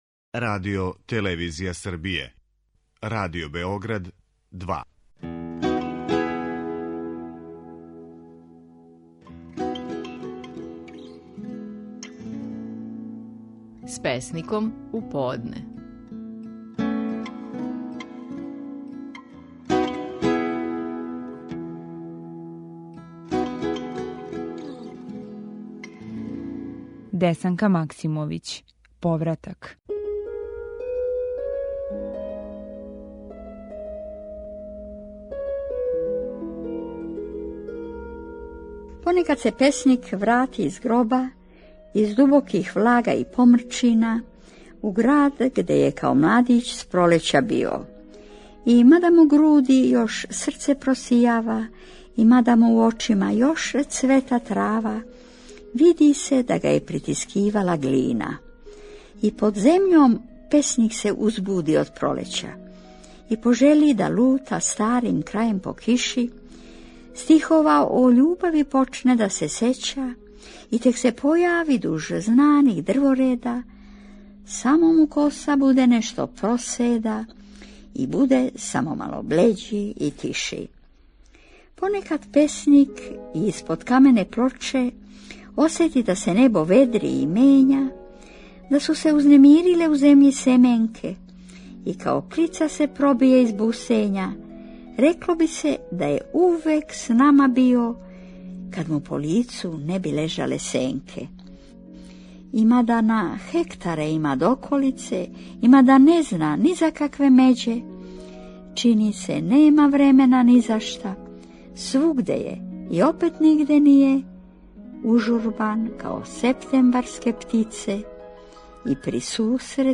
Стихови наших најпознатијих песника, у интерпретацији аутора.
Десанка Максимовић говори своју песму: „Повратак".